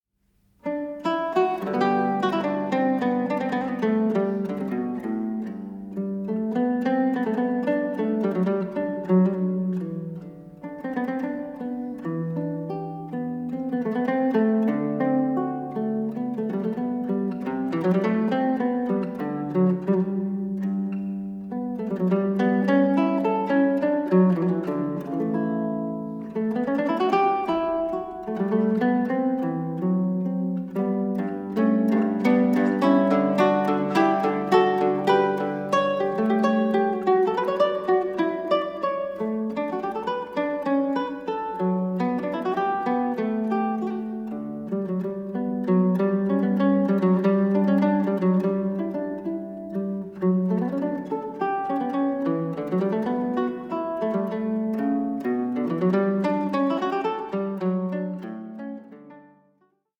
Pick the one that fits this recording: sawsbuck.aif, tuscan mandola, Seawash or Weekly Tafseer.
tuscan mandola